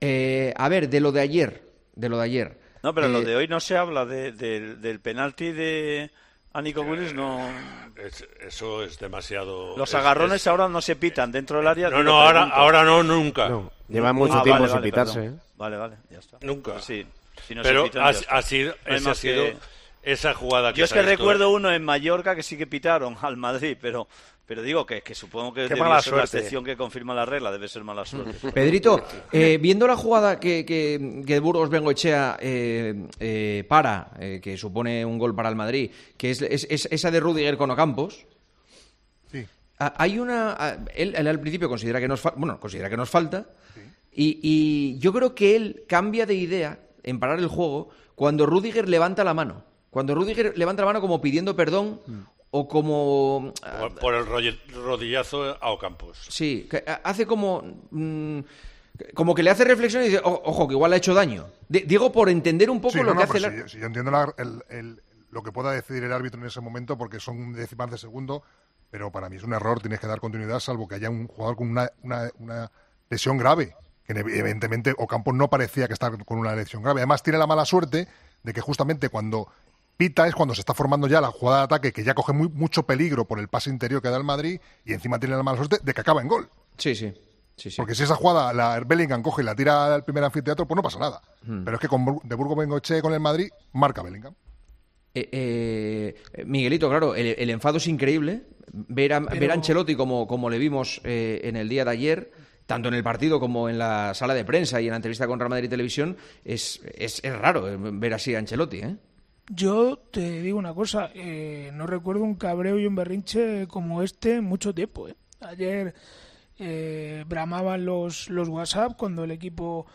El Tertulión de los domingos, en Tiempo de Juego, en directo a través de Youtube